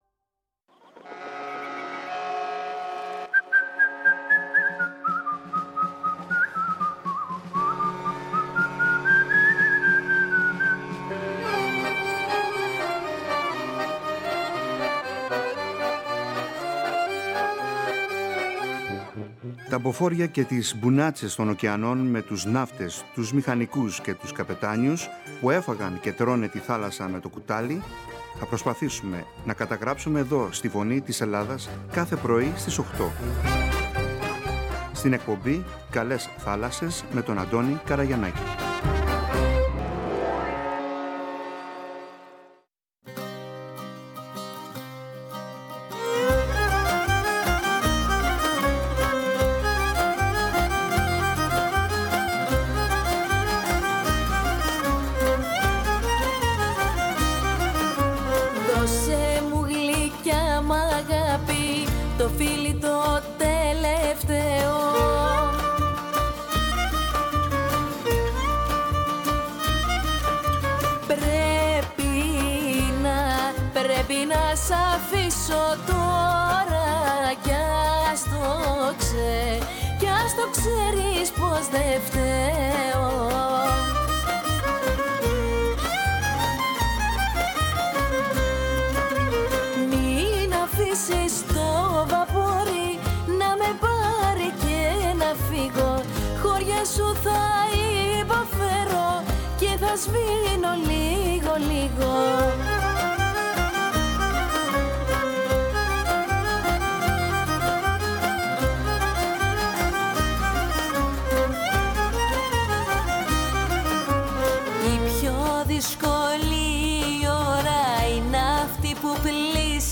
Μας διηγείται συναισθήματα, σκέψεις και ιστορίες από εκείνη την περίοδο της ζωής του και πρέπει να τα ακούσετε.